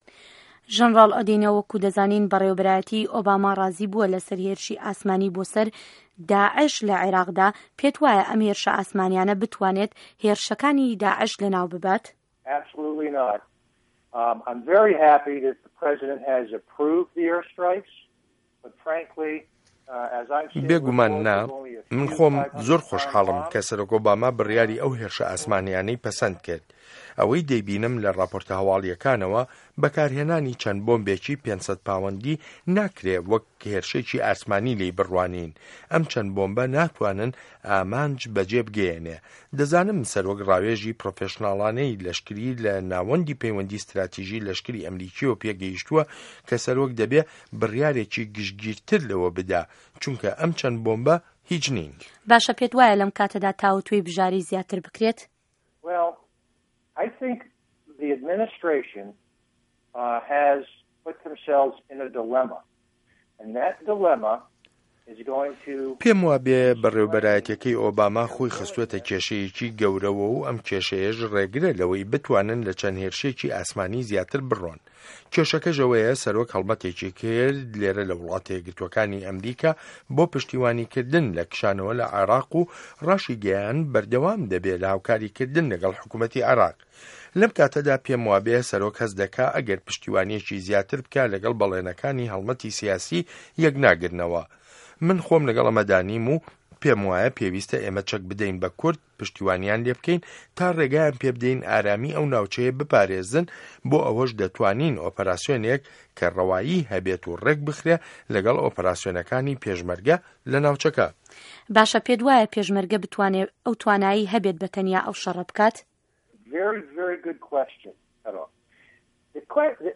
وتووێژ له‌گه‌ڵ ژه‌نه‌راڵ ئێرن ئه‌دینۆ